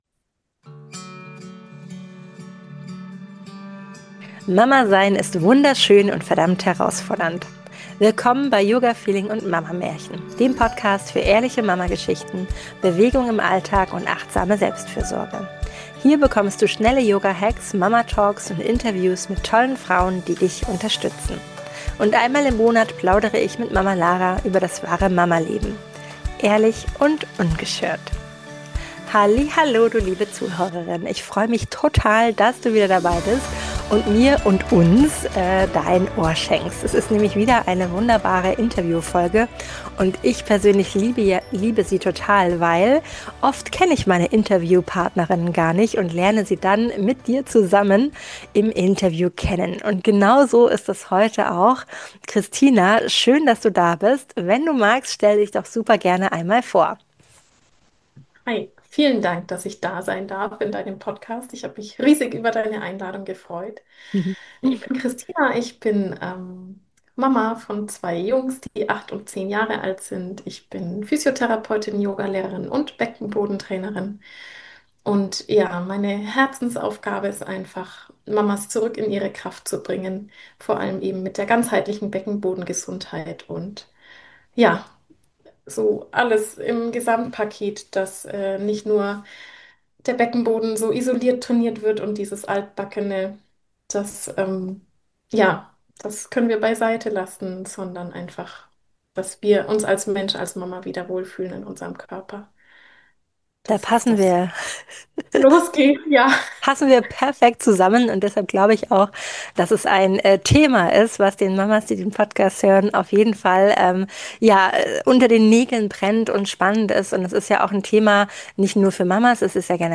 Wir reden darüber, warum Rückbildung eigentlich Rehabilitation heißen sollte, wie wichtig es ist, An- und Entspannung gleichermaßen zu üben und wie du deinen Beckenboden im Alltag spüren und stärken kannst. Am Ende wartet eine kleine Mitmach-Übung, die du sofort ausprobieren kannst.